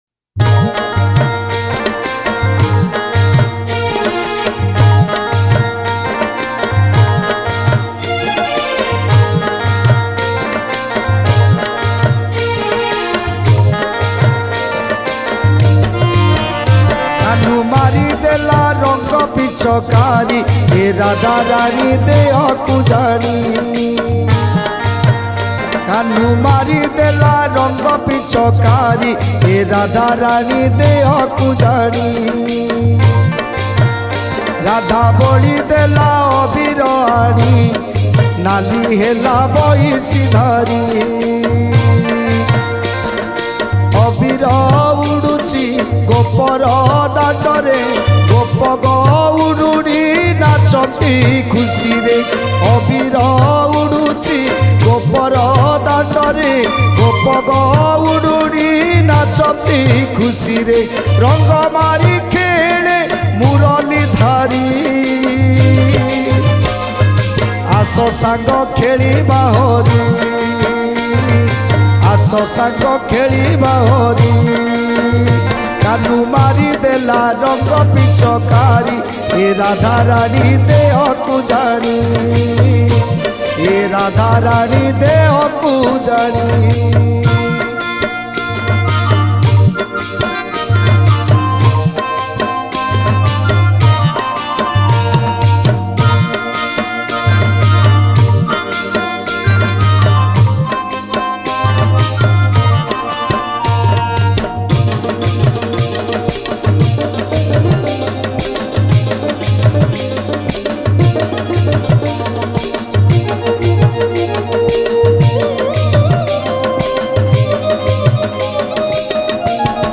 Odia Bhajan Song 2024 Songs Download